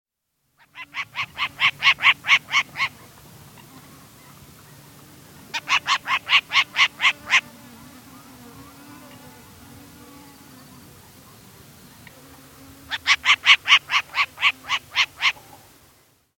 "Múcaro Real"
"Short-eared Owl"
Asio flammeus portoricensis
mucaro-real.mp3